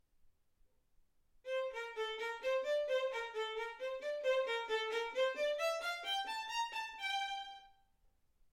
Hegedű etűdök Kategóriák Klasszikus zene Felvétel hossza 00:09 Felvétel dátuma 2025. december 8.